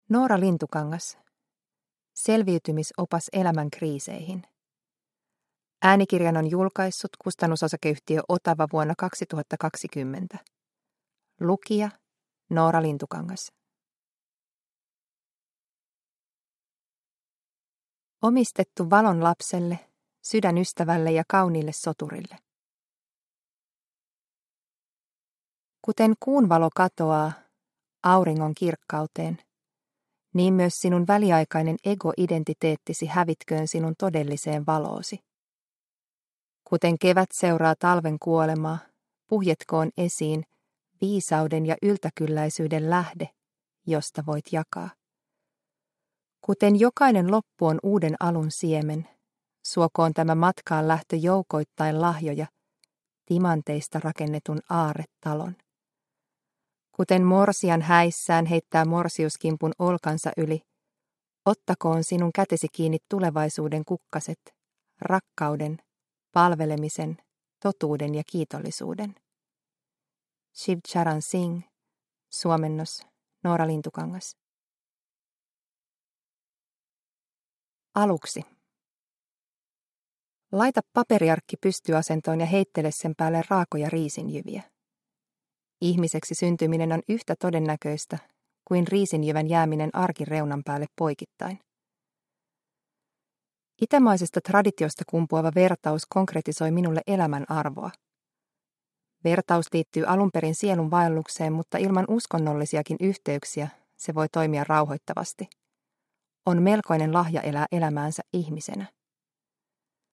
Selviytymisopas elämän kriiseihin – Ljudbok – Laddas ner